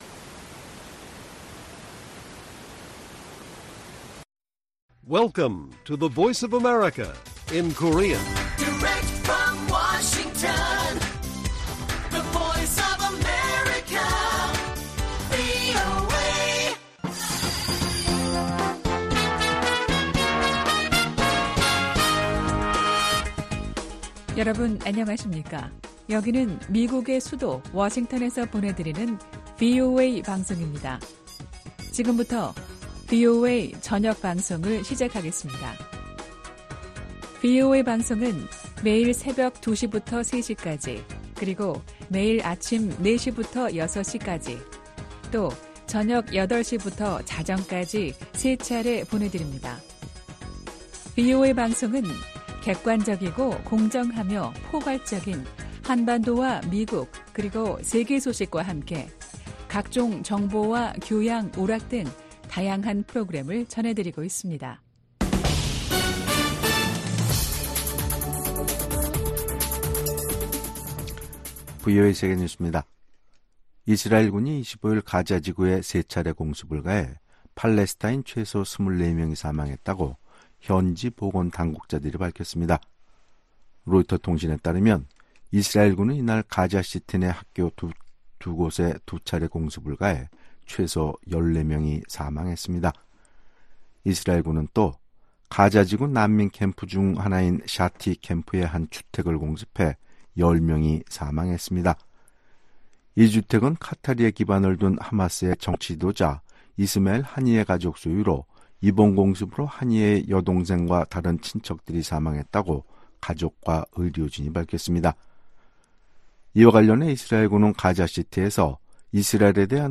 VOA 한국어 간판 뉴스 프로그램 '뉴스 투데이', 2024년 6월 25일 1부 방송입니다. 한국에 제공하는 미국의 확장억제 강화는 워싱턴 선언 이행으로 적절한 수준이라고 커트 캠벨 미 국무부 부장관이 말했습니다. 북한이 한국에 또 다시 '오물 풍선'을 살포하고 새로운 방식의 군사 도발 가능성도 내비쳤습니다.